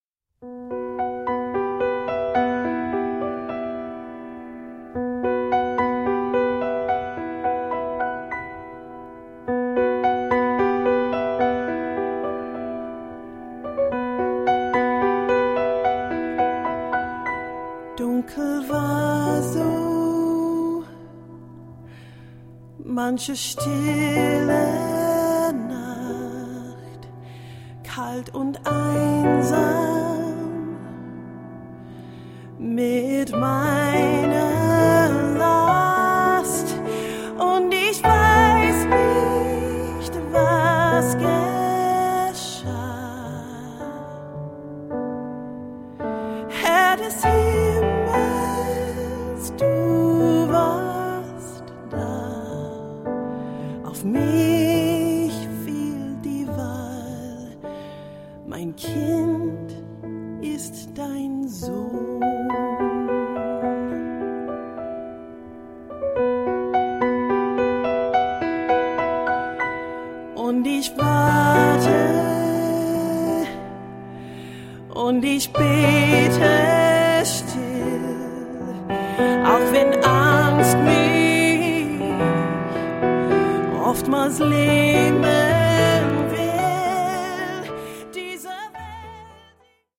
Piano
Pop